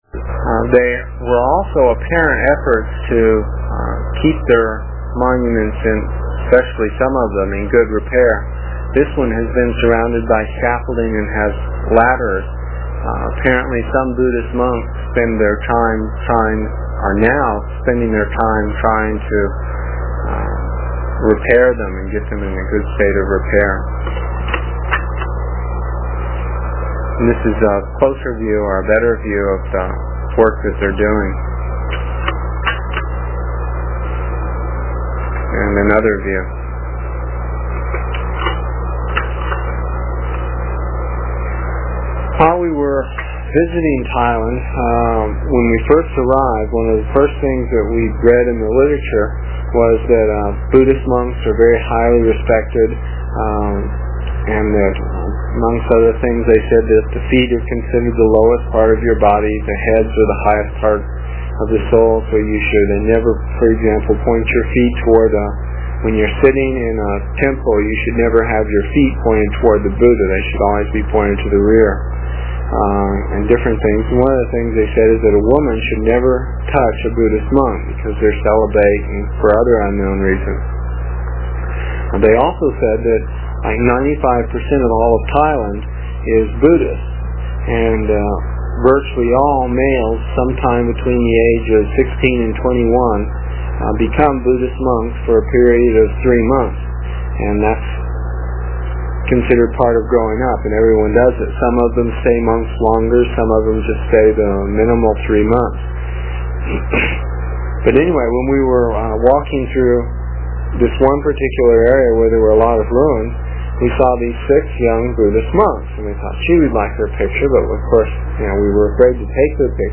It is from the cassette tapes we made almost thirty years ago. I was pretty long winded (no rehearsals or editting and tapes were cheap) and the section for this page is about eight minutes and will take about three minutes to download with a dial up connection.